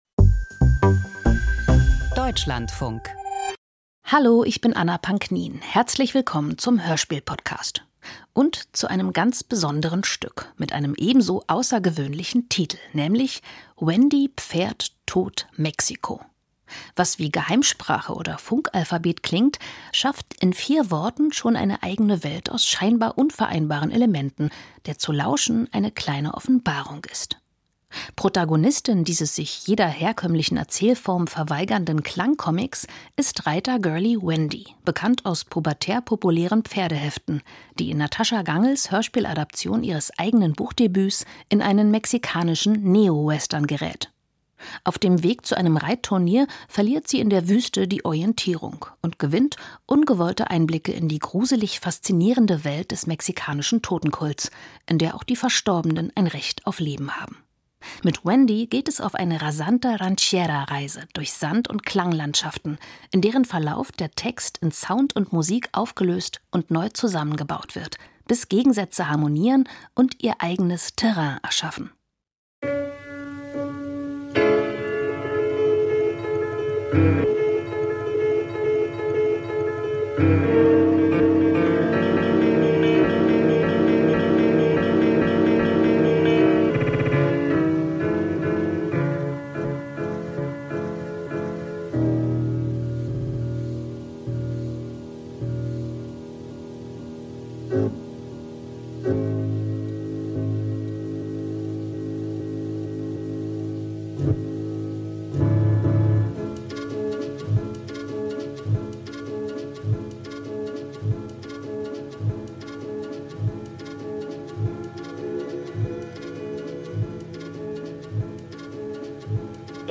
Neo-Western als Klangcomic - Wendy Pferd Tod Mexiko